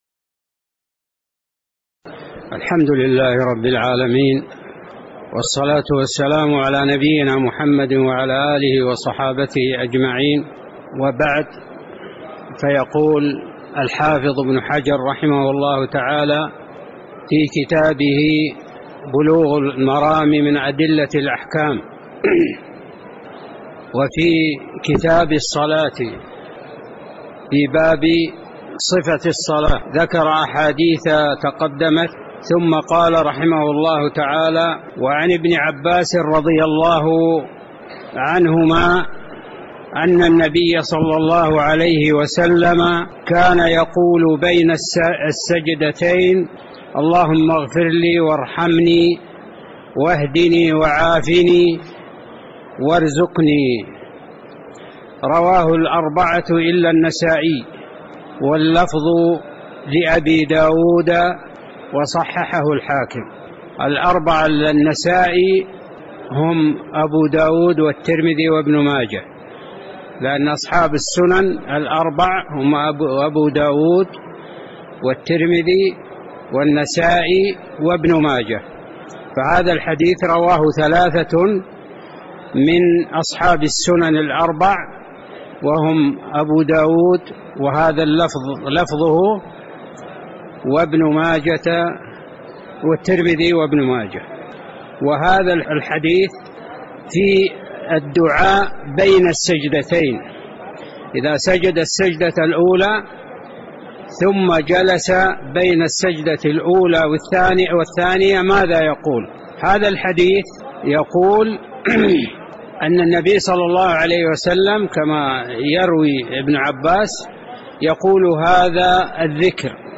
تاريخ النشر ١٢ صفر ١٤٣٩ هـ المكان: المسجد النبوي الشيخ